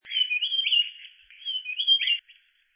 烏線雀鶥 Alcippe brunnea brunnea
錄音地點 高雄市 六龜區 扇平
錄音環境 森林
雄鳥歌聲
廠牌 Sennheiser 型號 ME 67